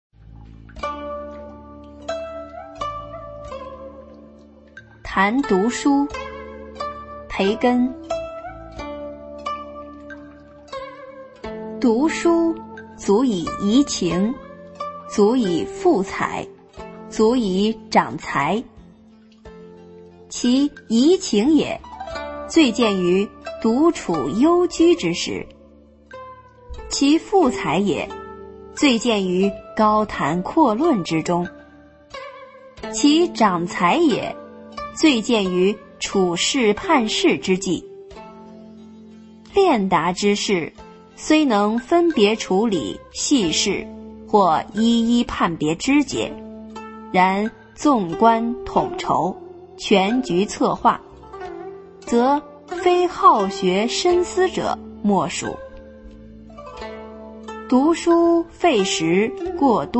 九年级语文下册13《谈读书》女声配乐朗读（音频素材）